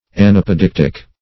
Search Result for " anapodeictic" : The Collaborative International Dictionary of English v.0.48: Anapodeictic \An*ap`o*deic"tic\, a. [Gr.
anapodeictic.mp3